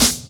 Snare_1_(Hard_Stepz_A).wav